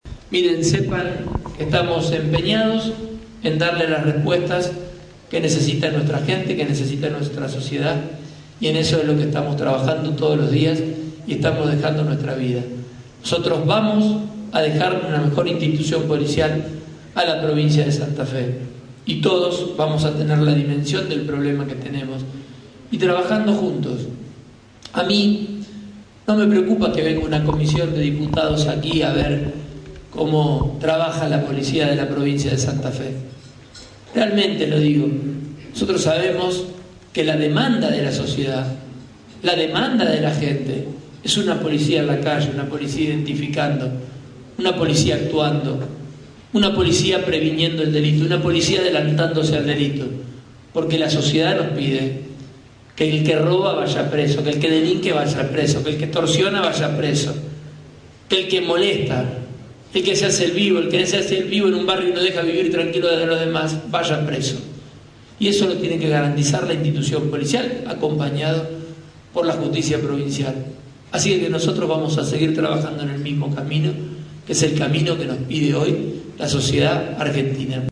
Polémica y desafortunada frase del Ministro de Seguridad, Maximiliano Pullaro, en su reciente visita a Vera.
El acto contó con la presencia del Ministro de Seguridad, Maximiliano Pullaro, quién en su discurso de unos 15 minutos habló sobre el esfuerzo y las inversiones que realiza la administración del gobernador Miguel Lifschitz en materia de seguridad.